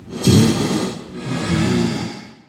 Minecraft Version Minecraft Version snapshot Latest Release | Latest Snapshot snapshot / assets / minecraft / sounds / mob / blaze / breathe4.ogg Compare With Compare With Latest Release | Latest Snapshot
breathe4.ogg